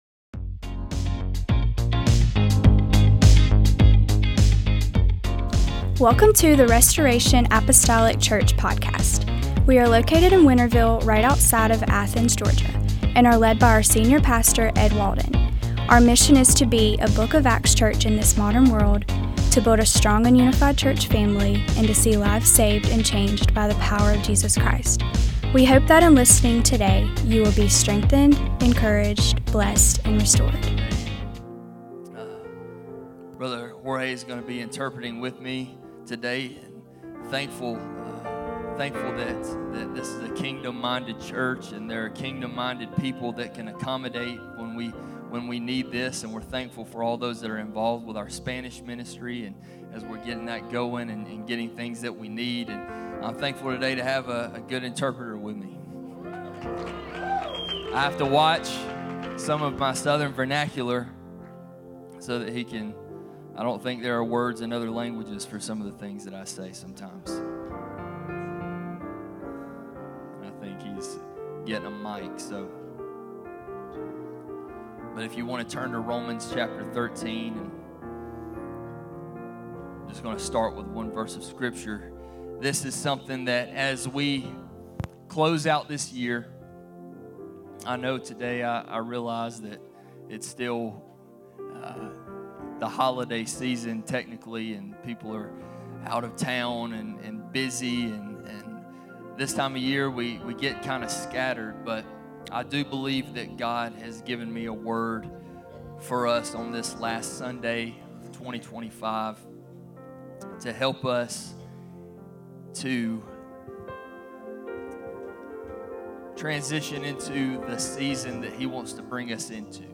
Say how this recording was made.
Sunday Service - 12/28/2025 - Asst.